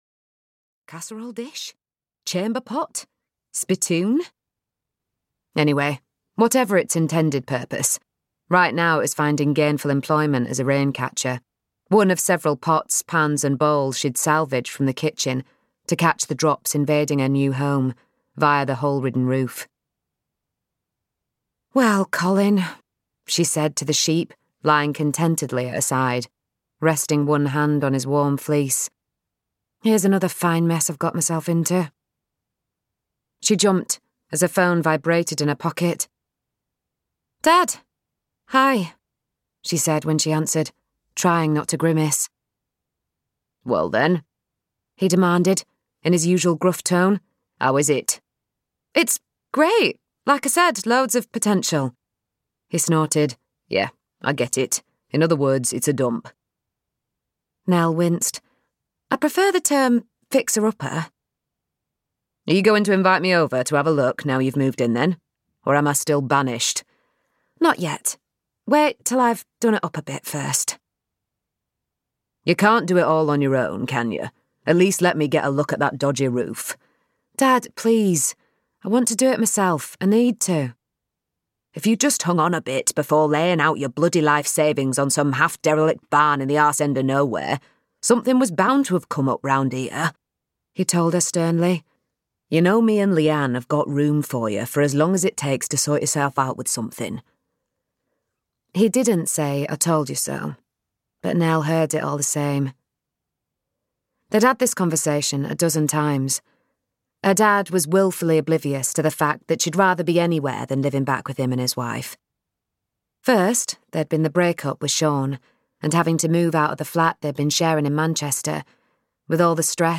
The School of Starting Over (EN) audiokniha
Ukázka z knihy